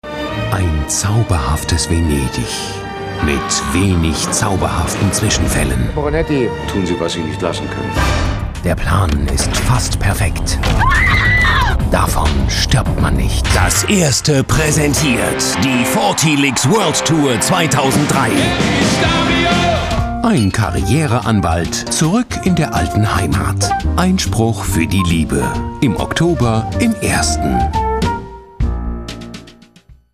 deutscher Sprecher. freiberuflicher Sprecher für ARD, ARTE, KIKA usw.
Sprechprobe: Werbung (Muttersprache):